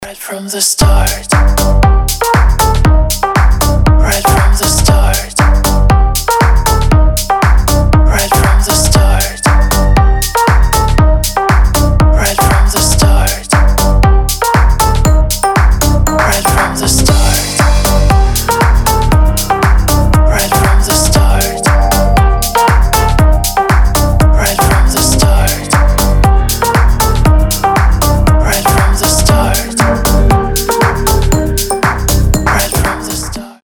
• Качество: 320, Stereo
ритмичные
EDM
чувственные
Bass
ксилофон
Стиль: deep house